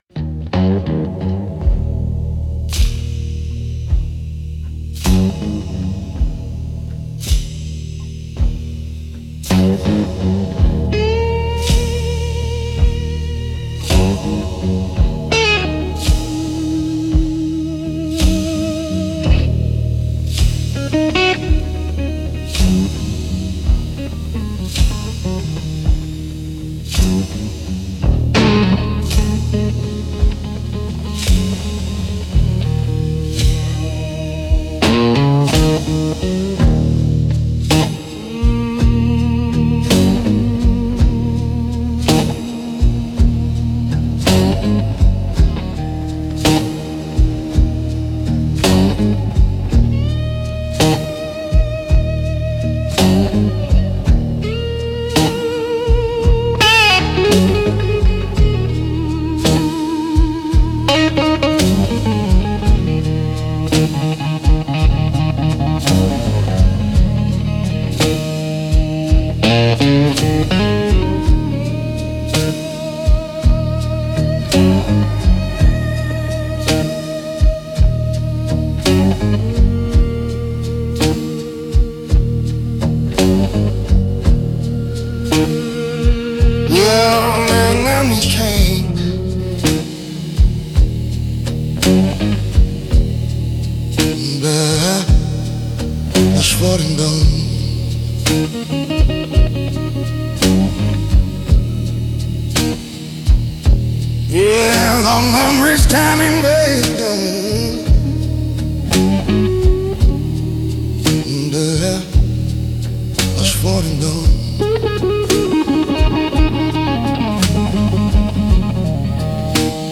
Instrumental - Where the Light Can’t Reach